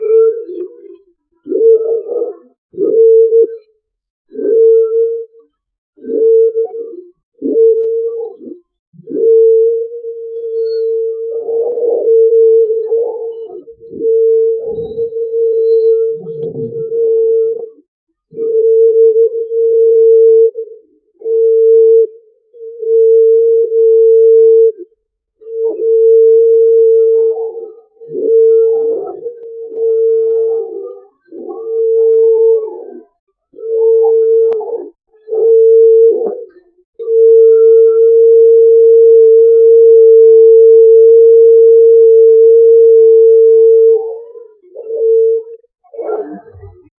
End with a rolling 75 Hz exhale. barnyard background 0:47 ultrasound sounds emitted by dolphins 0:47 Generate a deep, powerful bovine mating call.
ultrasound-sounds-emitted-pjtnr55x.wav